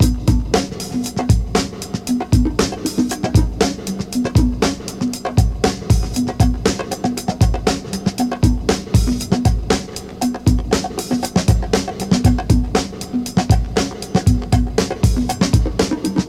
118 Bpm Drum Loop G# Key.wav
Free breakbeat sample - kick tuned to the G# note.
118-bpm-drum-loop-g-sharp-key-89w.ogg